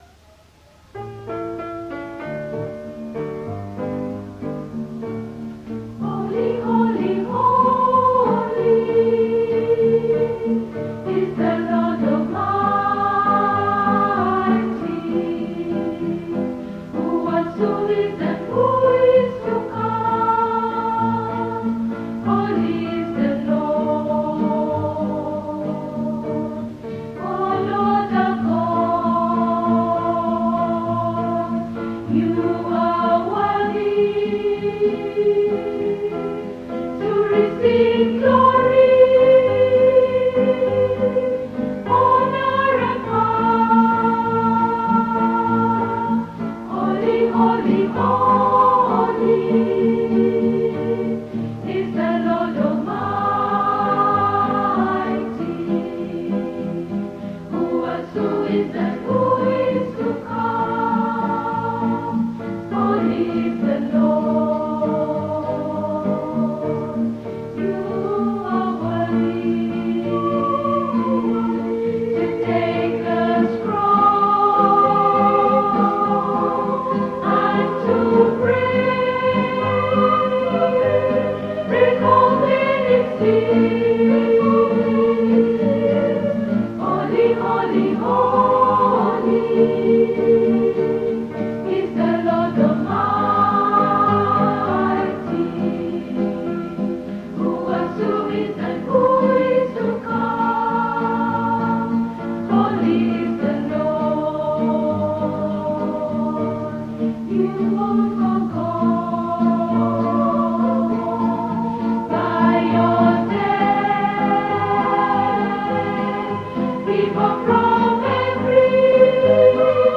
In their Hour of Praise they sang 27 items.